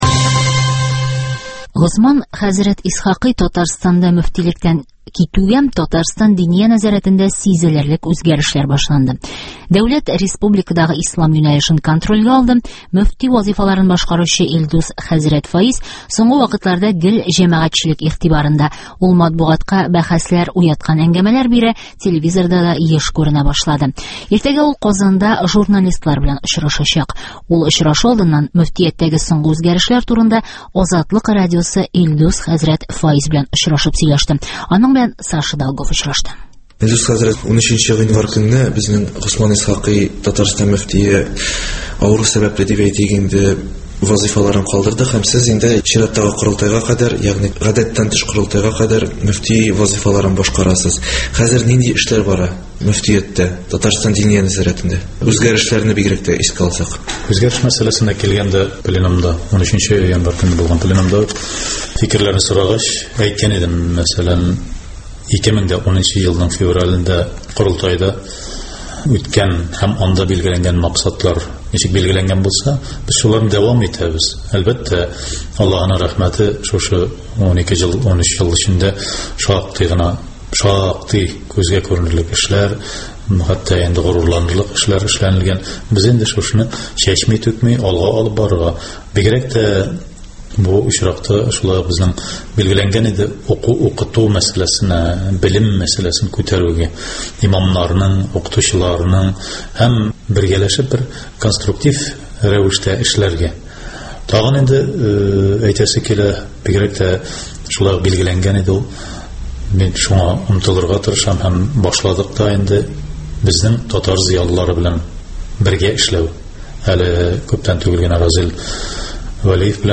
Илдус хәзрәт Фәиз белән әңгәмә (тулысынча)